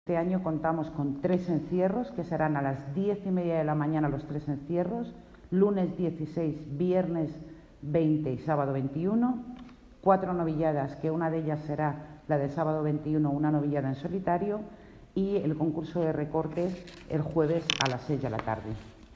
Declaraciones de la concejala de Fiestas